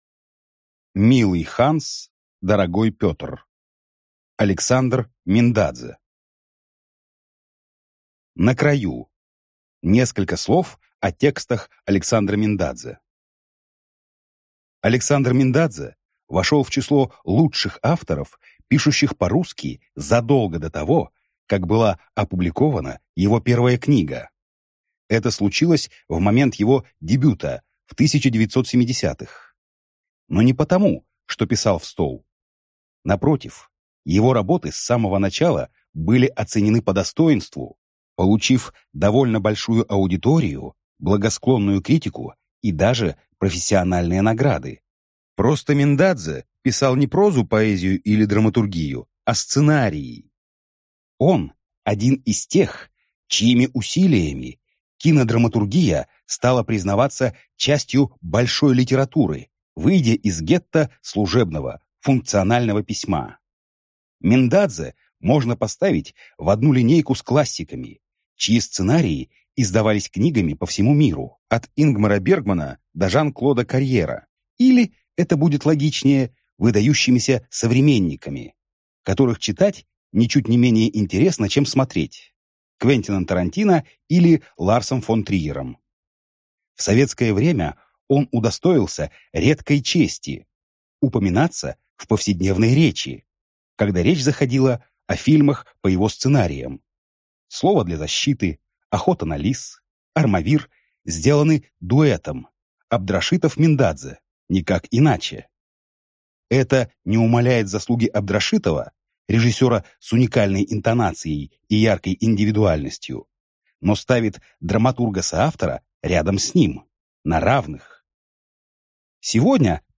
Aудиокнига Милый Ханс, дорогой Пётр